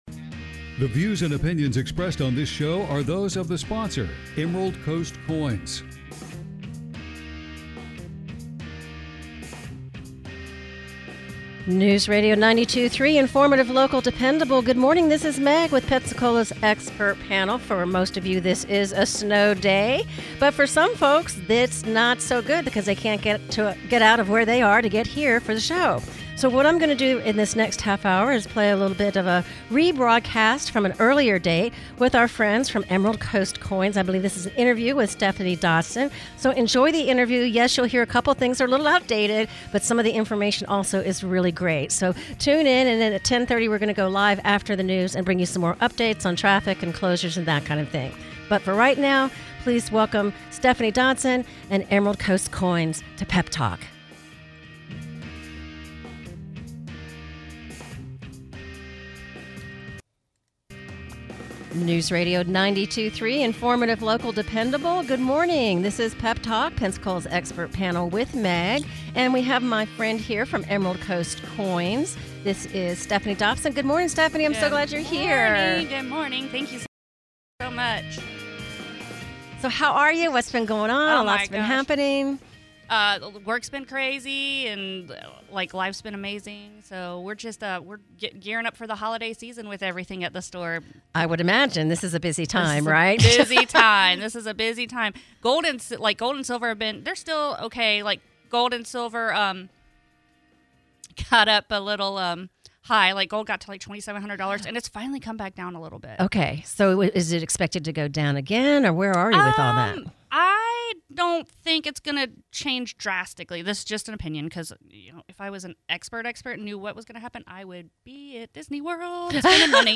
(Encore re-broadcast due to the inclement weather.)&nbsp